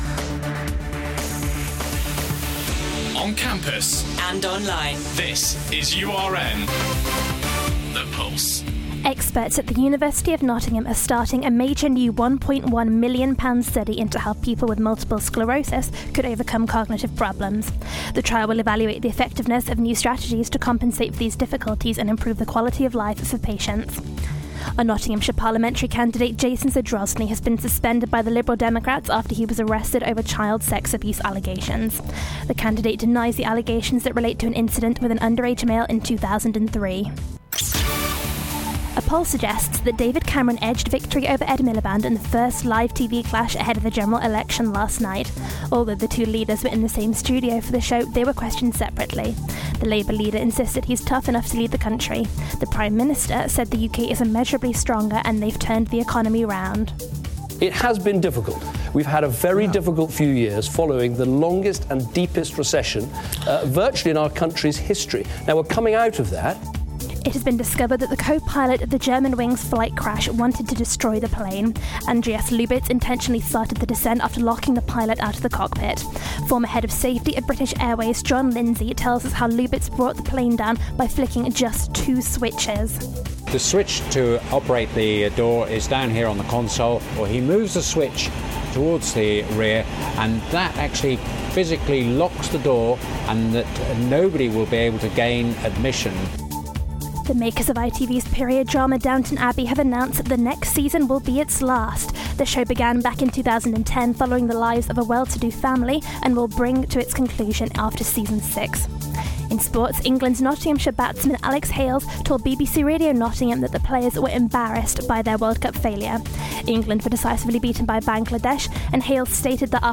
All of your latest headlines on URN